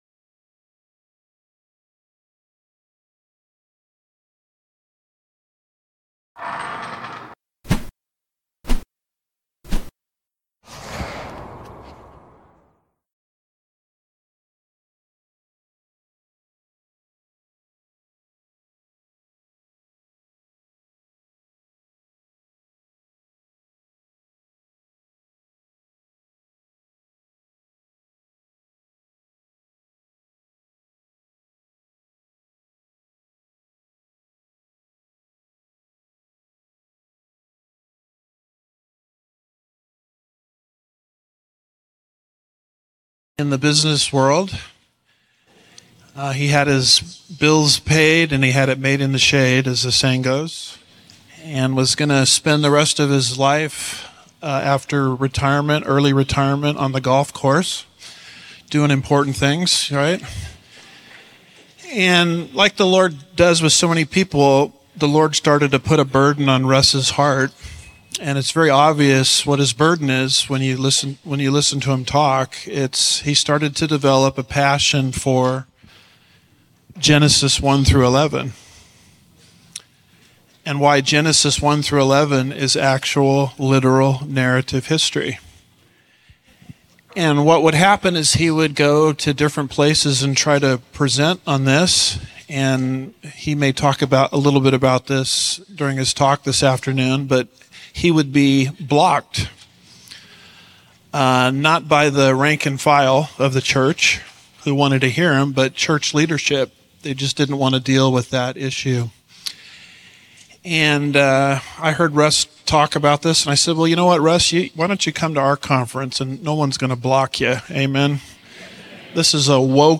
2026 Prophecy Conference